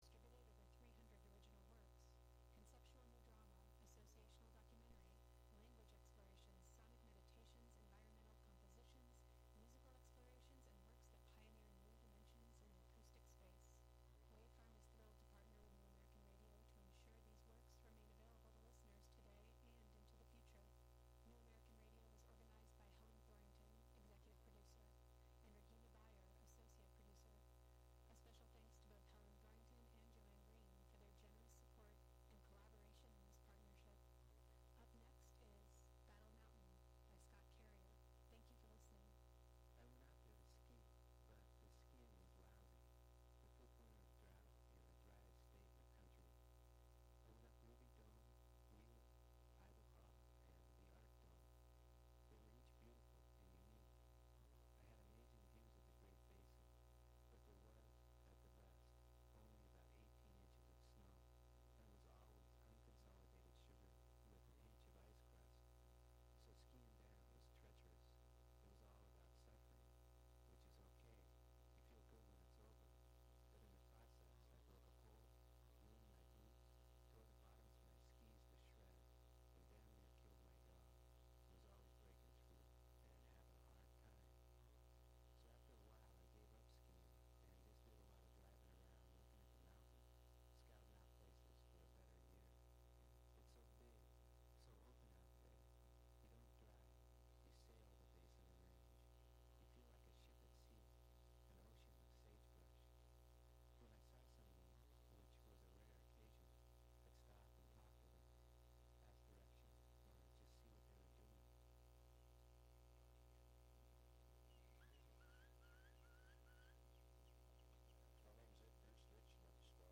broadcast live from WGXC's Hudson studio.